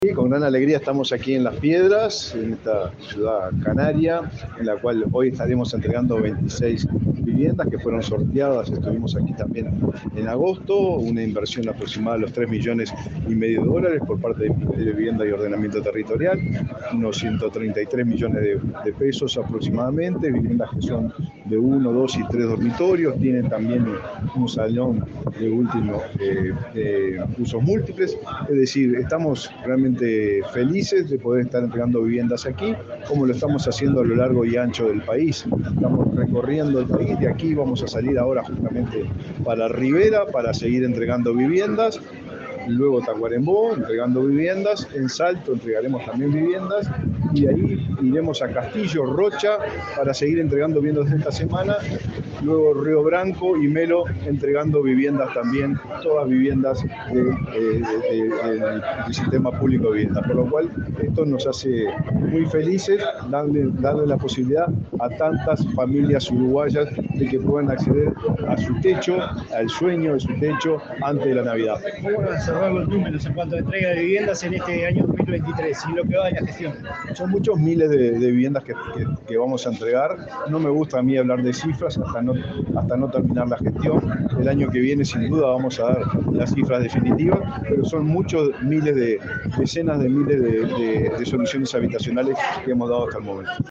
Declaraciones del ministro de Vivienda, Raúl Lozano
Declaraciones del ministro de Vivienda, Raúl Lozano 18/12/2023 Compartir Facebook X Copiar enlace WhatsApp LinkedIn Este lunes 18, el ministro de Vivienda, Raúl Lozano, dialogó con la prensa en Canelones, antes de participar en el acto de entrega de 26 viviendas en la modalidad de alquiler con opción a compra, en la localidad de Las Piedras.